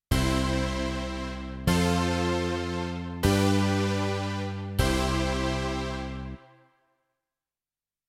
♪ハ長調の和音進行(mp3)